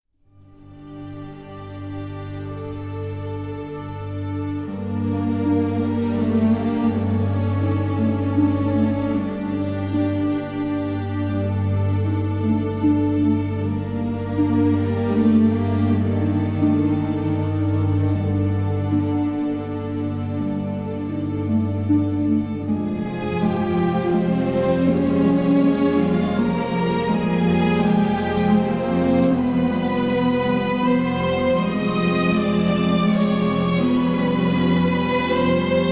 jazz compositions